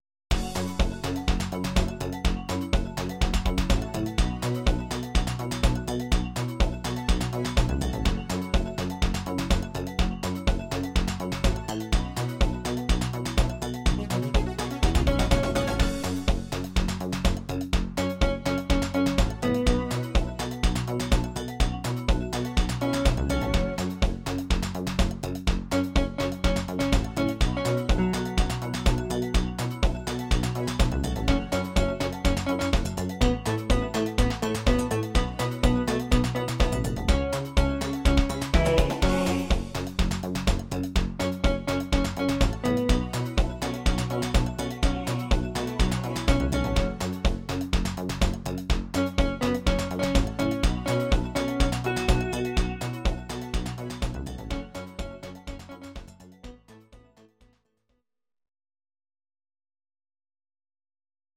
Audio Recordings based on Midi-files
Our Suggestions, Pop, 1980s